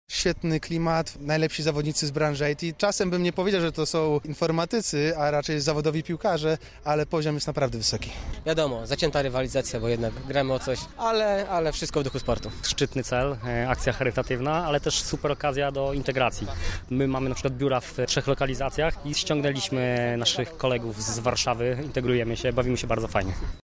O szczegółach mówią uczestnicy turnieju.